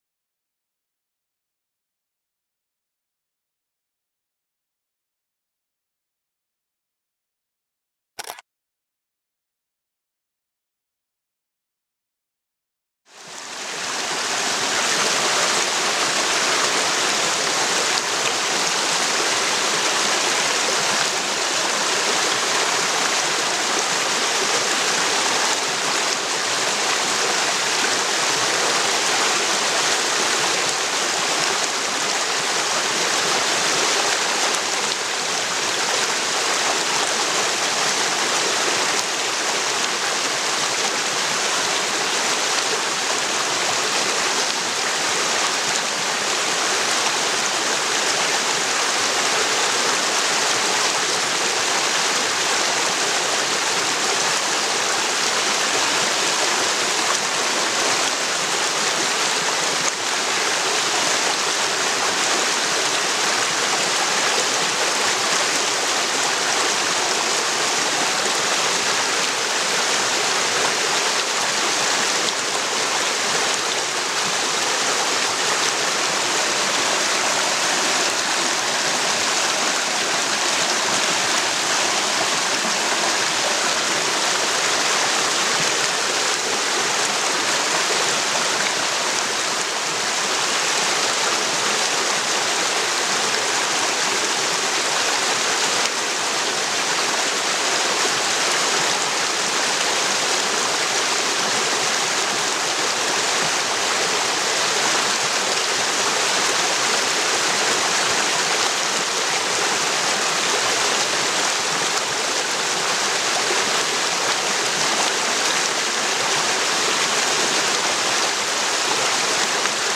STRESS-WEGKLOPFER: Beruhigender Fensterregen klopft Sorgen weg